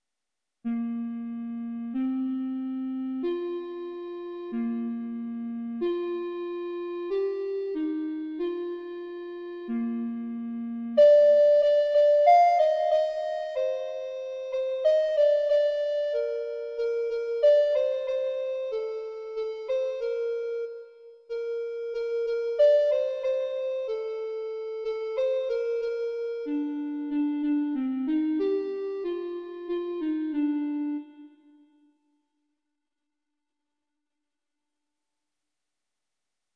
Canon voor 3 stemmen
Als driestemmige canon